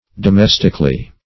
Domestically \Do*mes"tic*al*ly\, adv.